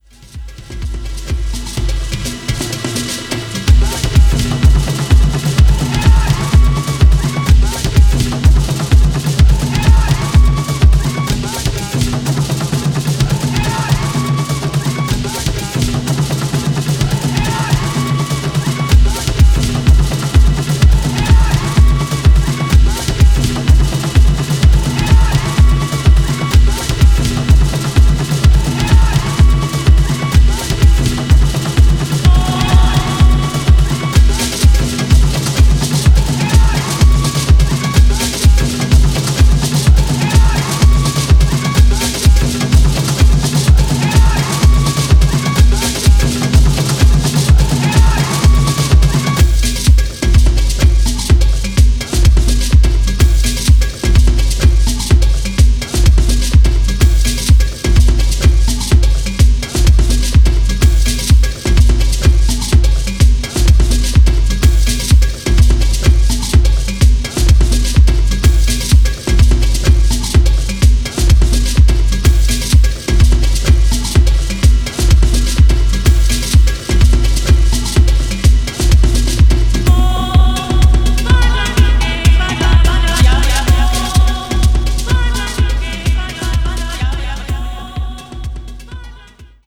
アフロ・オリエンテッドな要素とボトムヘヴィなリズムを組み合わせたダークでエネルギッシュなトラック群を展開しており